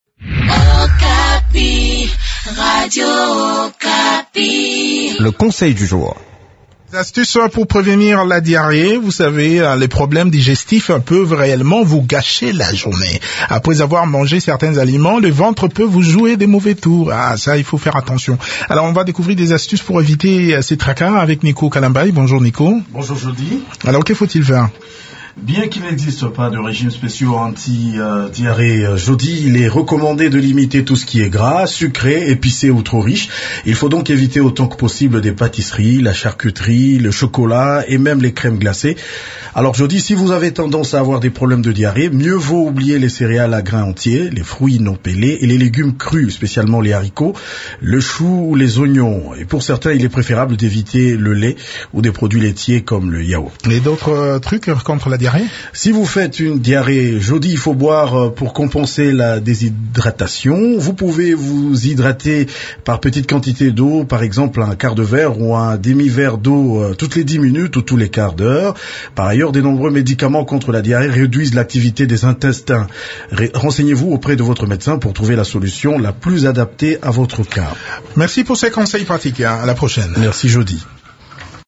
Découvrez des astuces pour  éviter ces tracas dans cette chronique